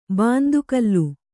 ♪ bāndu kallu